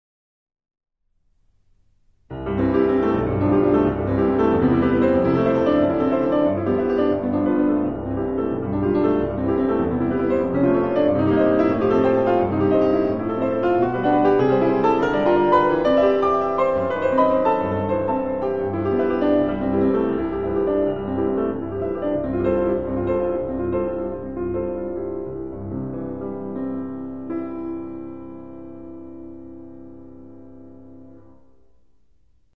Fryderyk Chopin - Prelude Op. 28 - No 1 in C major Agitato + midi file